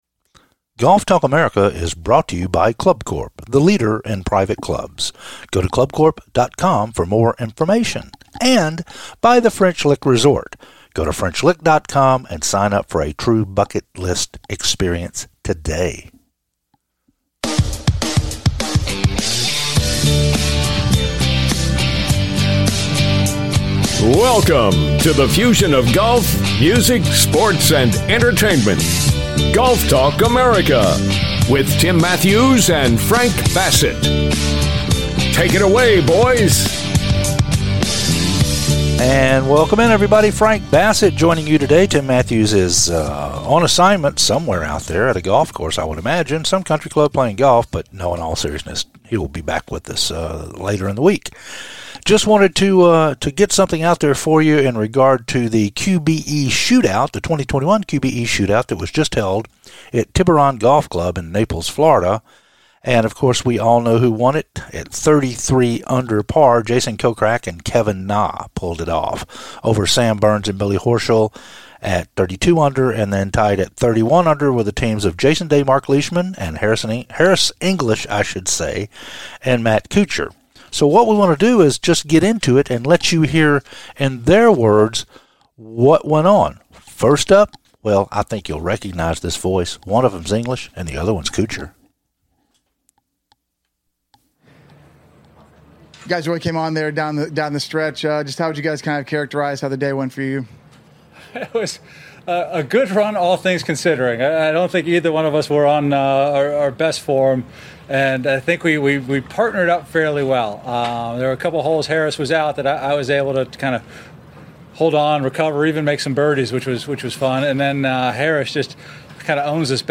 THE QBE SHOOTOUT "INTERVIEW SHOW"
Hear from the winners after their final round of THE QBE SHOOTOUT in their own words!